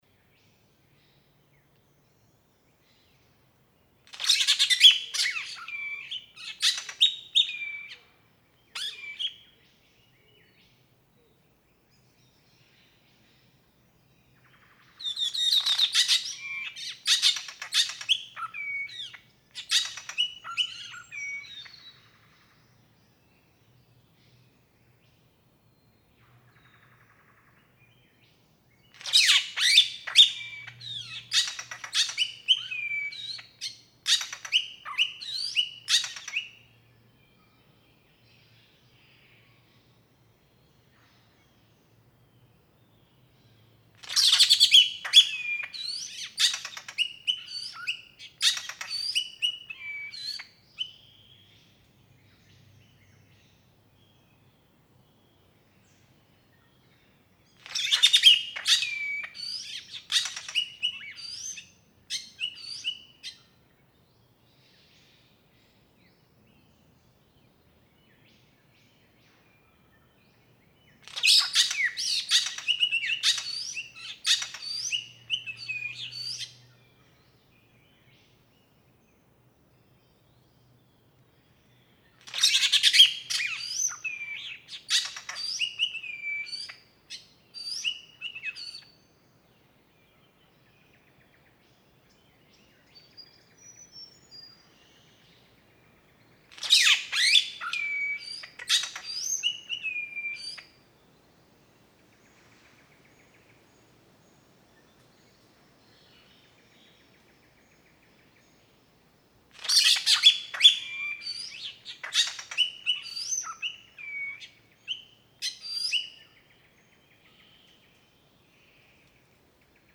Tooth-billed bowerbird
In the mountainous forests of northeast Queensland, the tooth-bill creates his display court in a cleared stage, with at least one tree trunk for perching, decorating the stage with fresh green leaves that are laid mostly so that the pale sides face up. And he sings, throughout much of the day, as he is always seeking a mate (see p. 26).
I love scrolling through his singing performance in Raven Lite—I see various themes that he sings for a while before actually switching to another.
Lake Eachem, Queensland.
718_Tooth-billed_Bowerbird.mp3